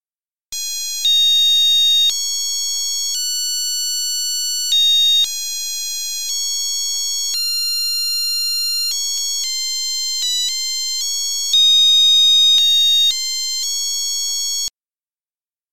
einem fröhlichen Seemannslied